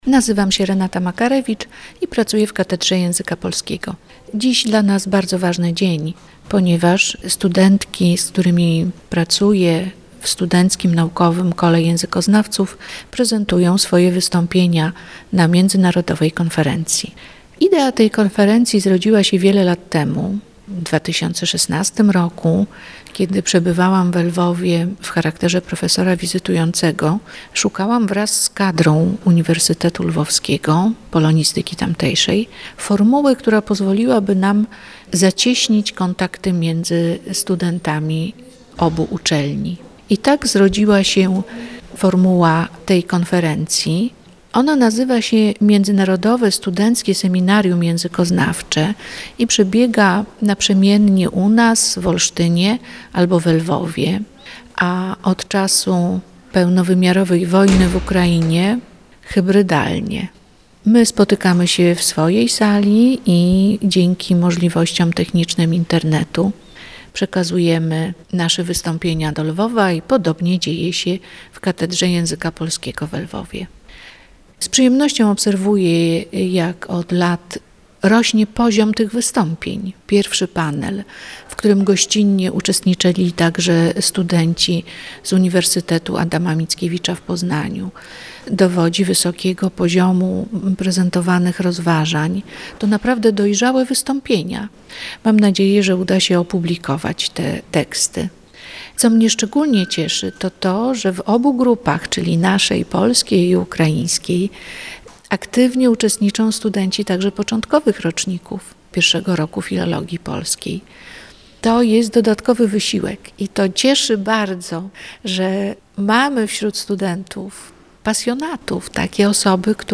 Nasza reporterka rozmawiała także z uczestnikami seminarium.